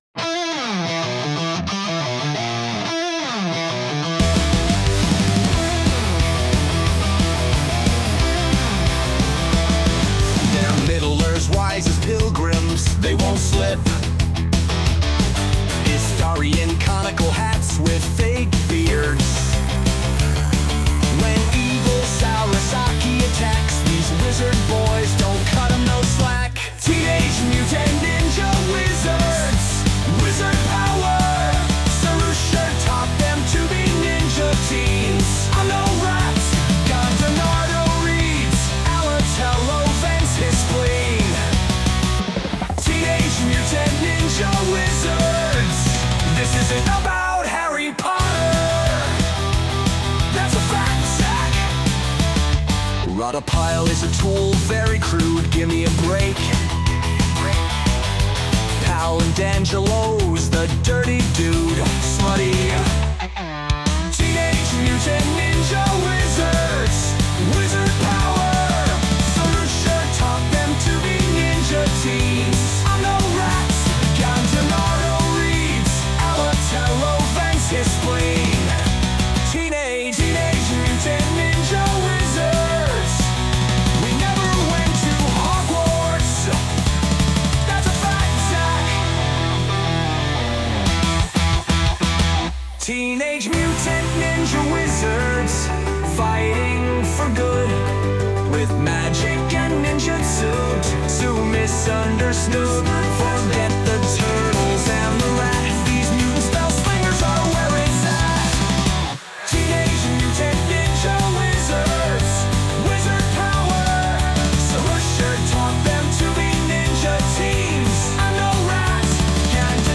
The rest is all A.I.